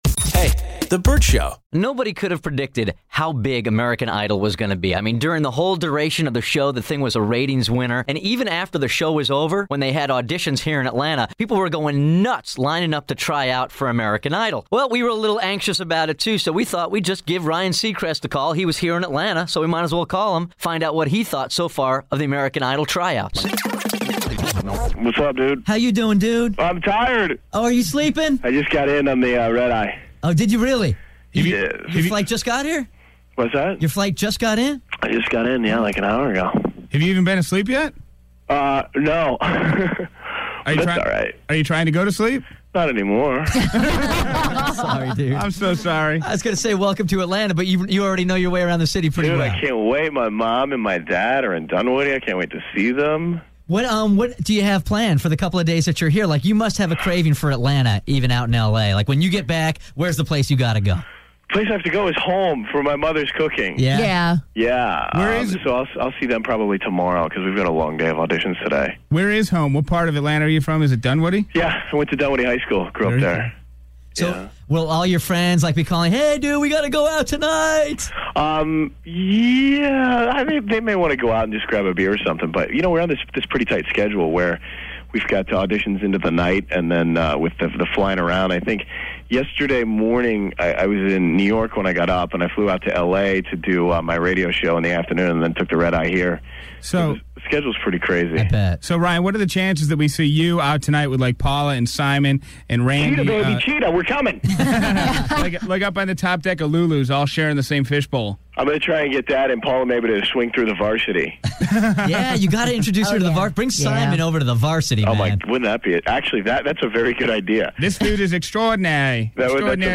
Vault: Interview - Ryan Seacrest